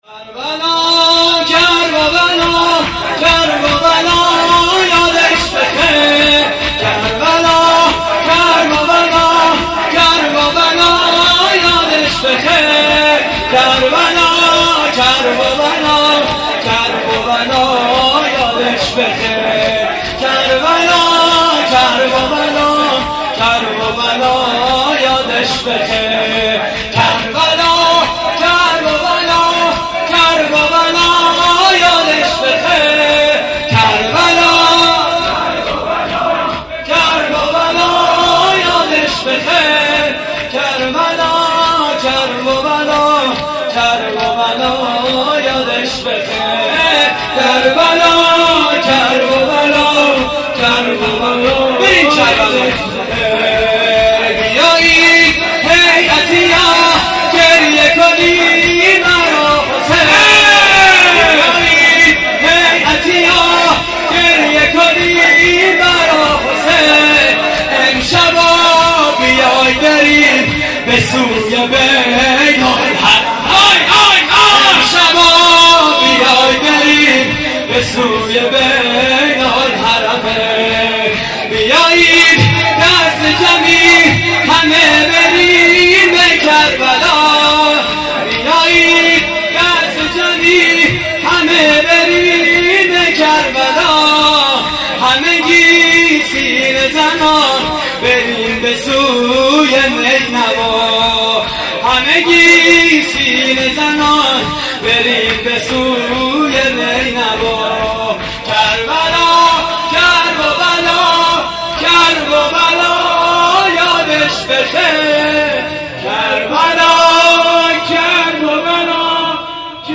شور: کرببلا یادش بخیر
مراسم عزاداری شب عاشورای حسینی (محرم 1432)